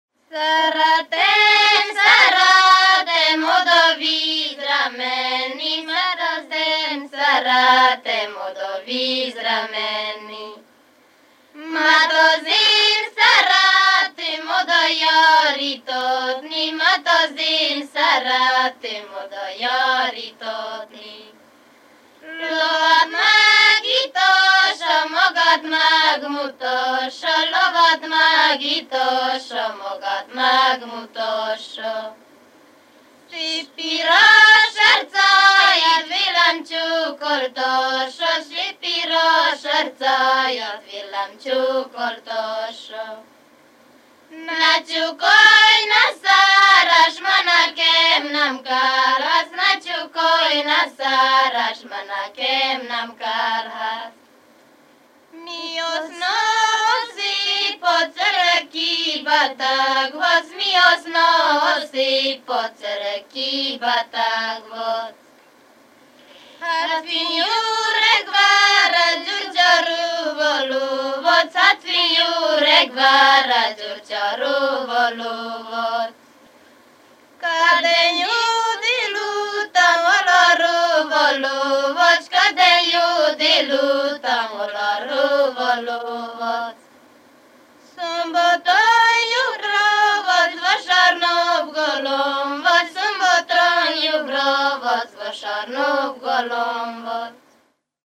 ének
Moldva (Moldva és Bukovina)